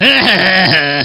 Line of Krunch in Diddy Kong Racing.
Krunch_(overtaking)_3.oga.mp3